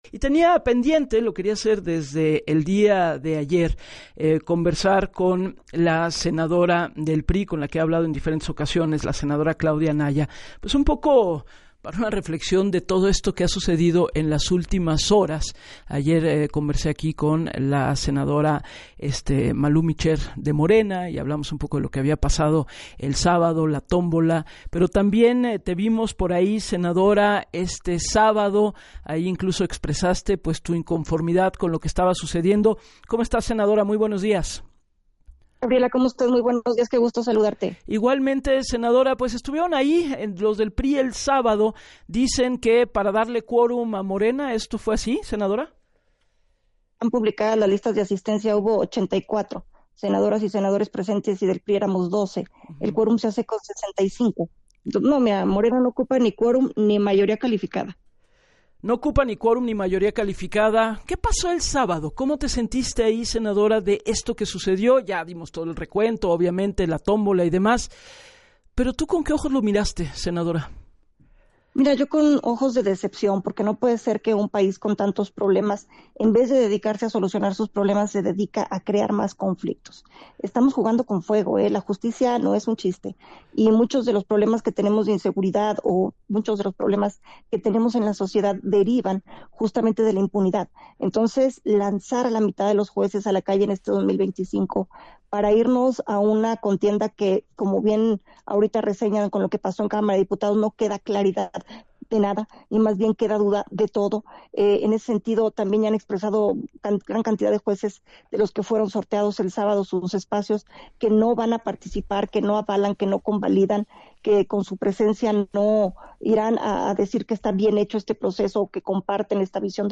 En el espacio de “Así las Cosas” con Gabriela Warkentin, puntualizó que “muchos de los problemas que tenemos de inseguridad y en la sociedad derivan de la impunidad”, por lo cual, “lanzar a la mitad de los jueces a la calle en un proceso falto de claridad”.